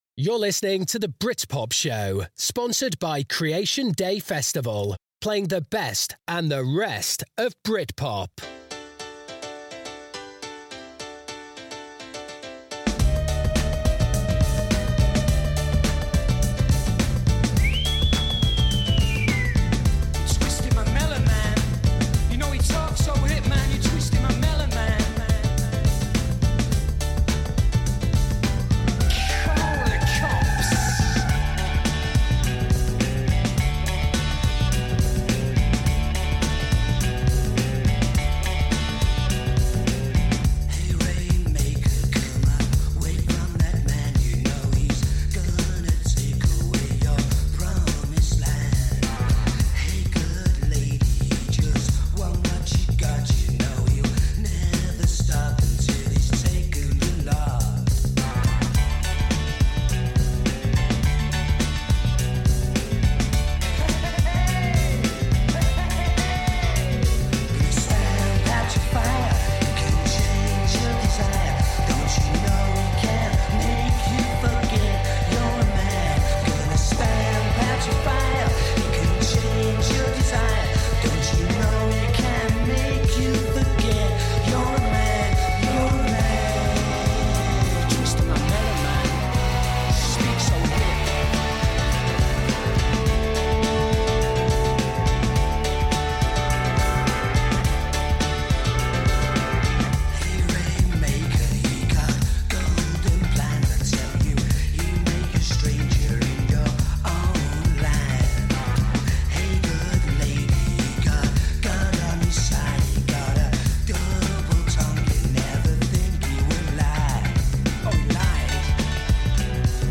A mix of classic Britpop tracks, Britpop songs you thought you’d forgotten, and some you ought to know but don’t yet - with the odd interview with Britpop royalty thrown in!